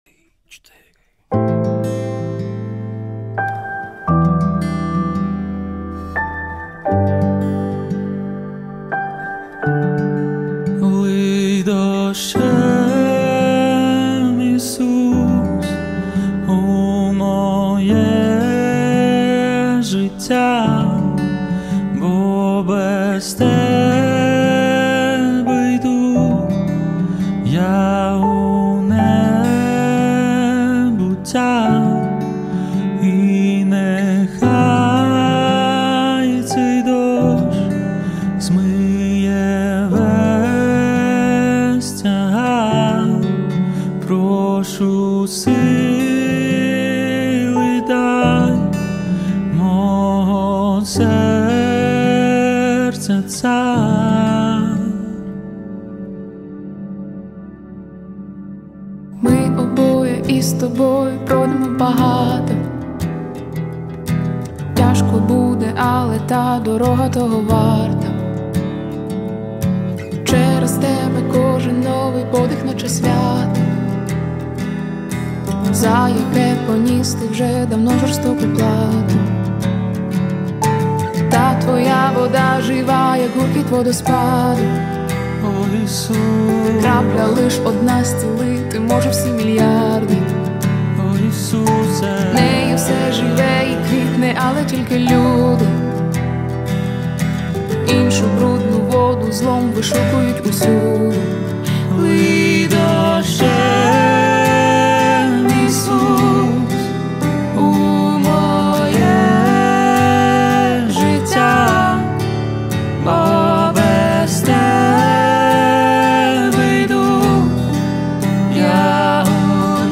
145 просмотров 184 прослушивания 19 скачиваний BPM: 174